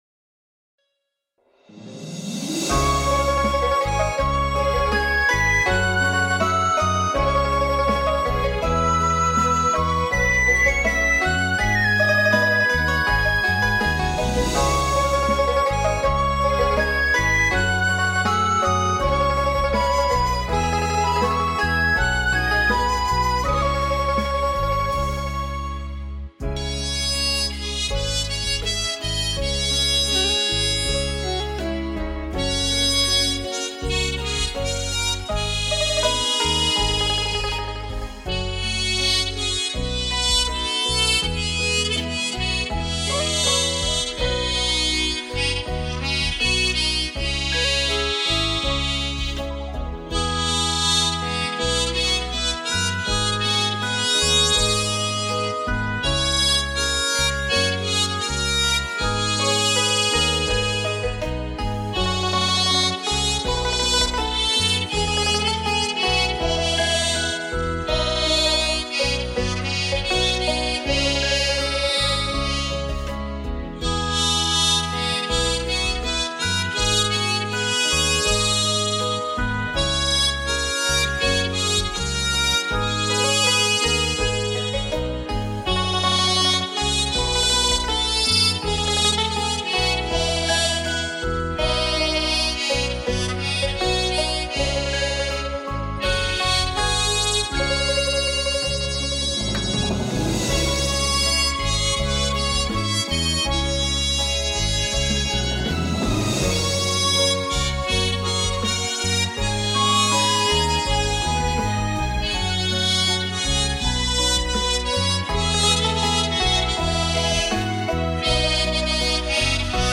梅雪吟-口风琴--未知 冥想 梅雪吟-口风琴--未知 点我： 标签: 佛音 冥想 佛教音乐 返回列表 上一篇： 叹十声-古筝--未知 下一篇： 五月的风-口风琴--未知 相关文章 《妙法莲华经》妙庄严王本事品第二十七 《妙法莲华经》妙庄严王本事品第二十七--佚名...